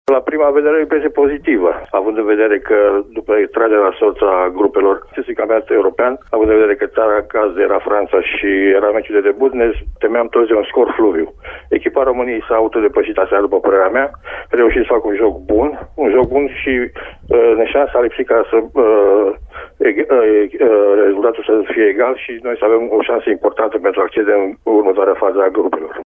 Marcel Coraș a vorbit astăzi despre debutul României la EURO 2016, în cadrul emisiunii Arena Radio de la Radio Timișoara.